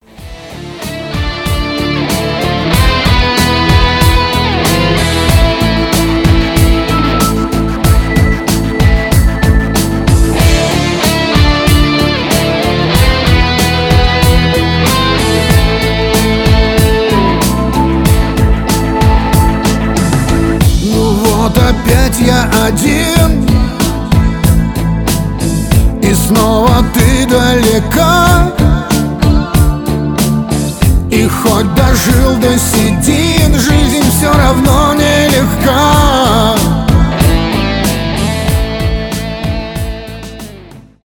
грустные , шансон , рок